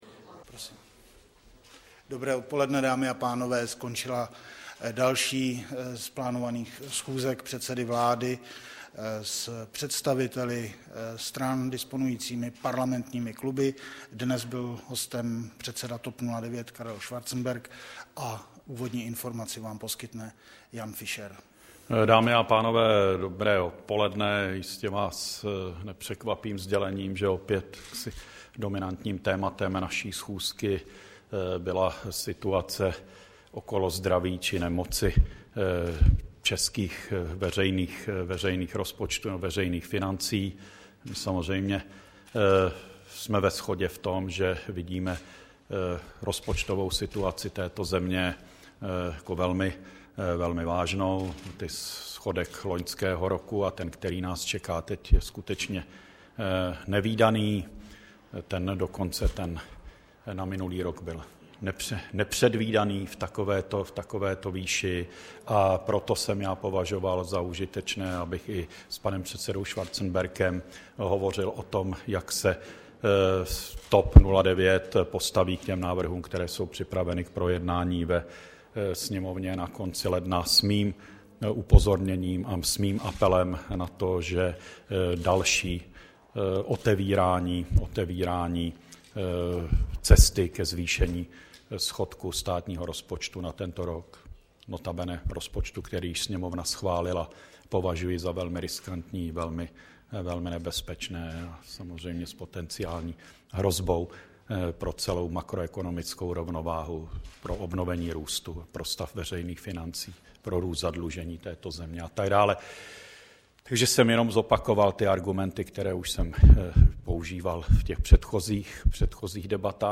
Tiskový brífink po jednání s Karlem Schwarzenbergem, 13. ledna 2010